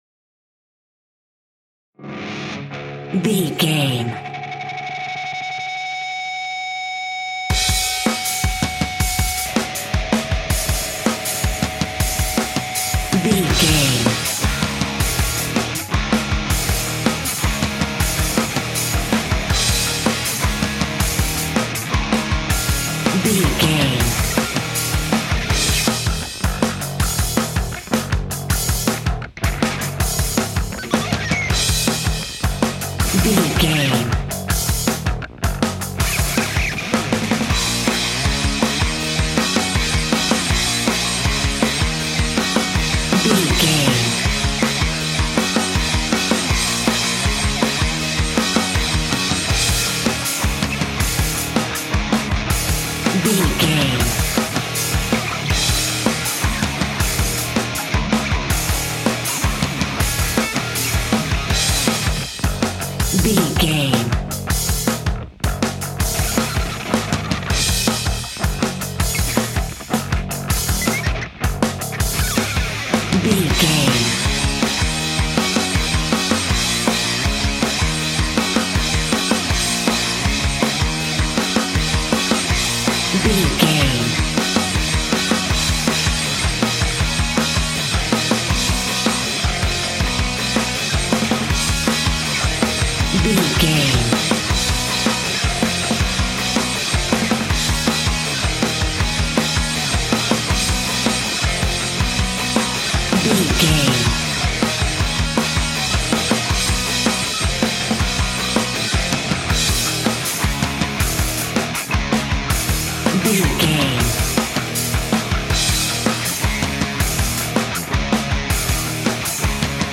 Aeolian/Minor
E♭
hard rock
heavy metal
blues rock
distortion
Rock Bass
Rock Drums
heavy drums
distorted guitars
hammond organ